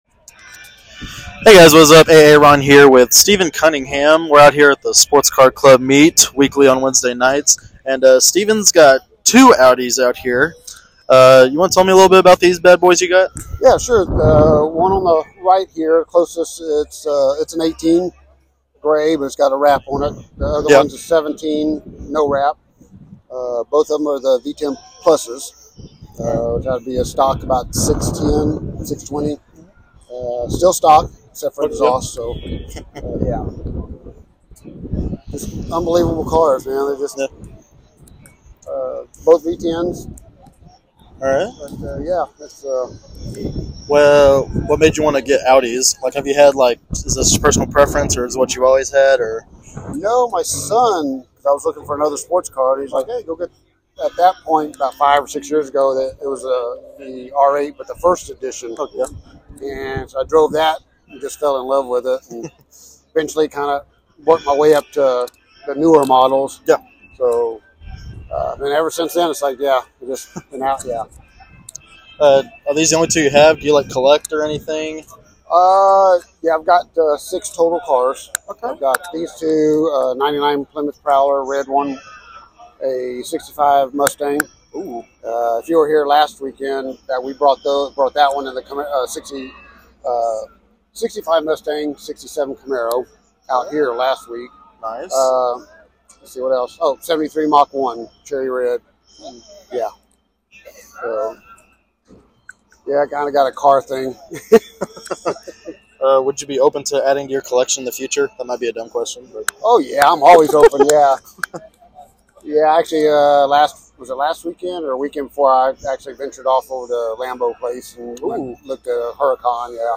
An interview with Texas A&M Sports Car Club and business owner